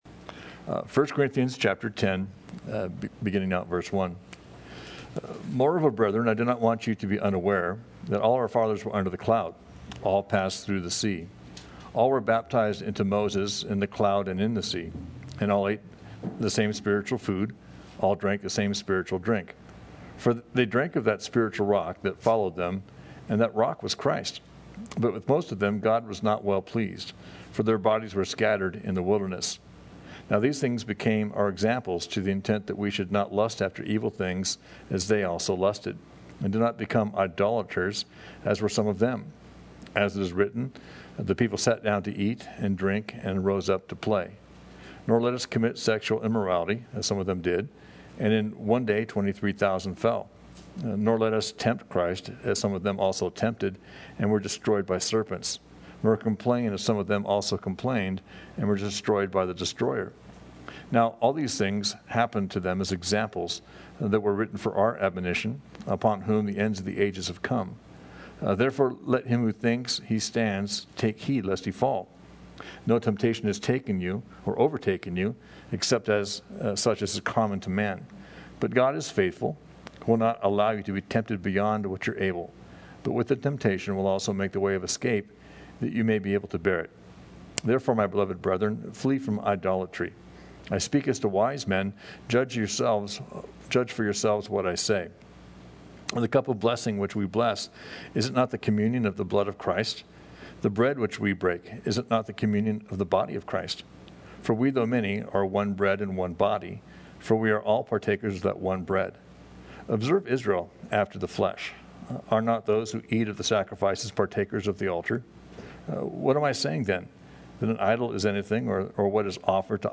Thank’s for checking out our study in 1st Corinthians.